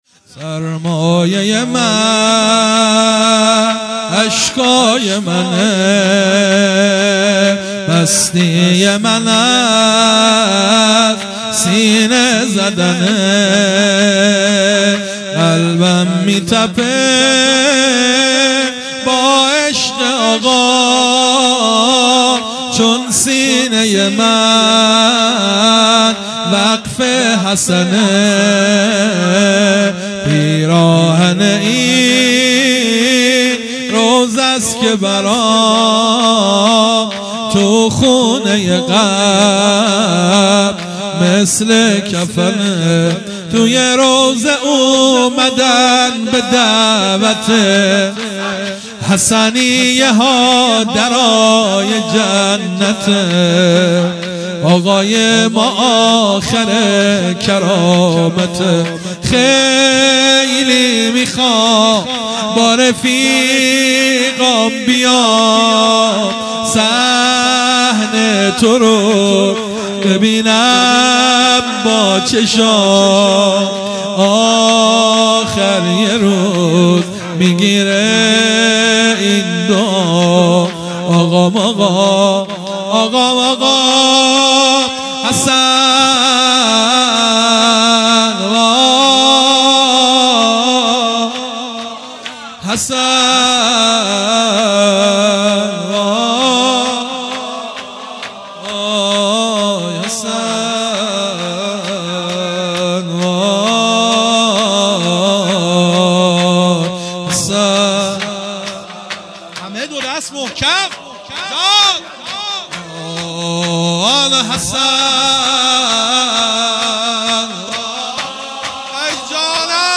زمینه |سرمایه من اشکای منه